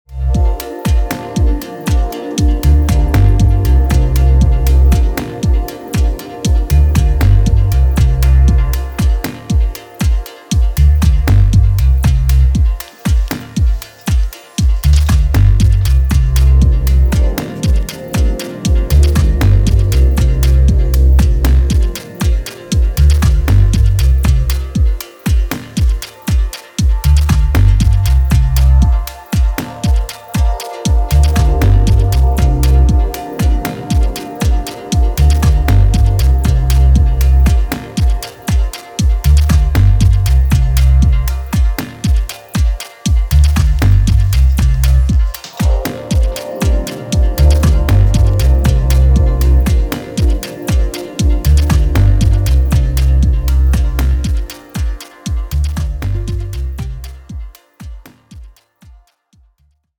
諸要素を絞り、音響のサイケデリックな効果に注力したミニマル・ハウスのモダンな最新形。